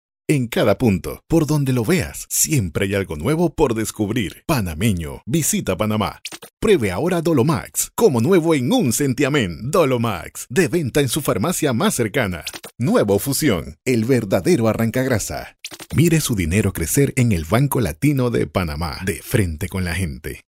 Voz Potente y VersĂĄtil
spanisch SĂŒdamerika
Sprechprobe: Werbung (Muttersprache):